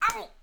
SFX_Battle_Vesna_Defense_08.wav